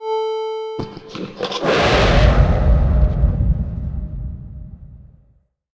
spearYellRumbleVoicePA.ogg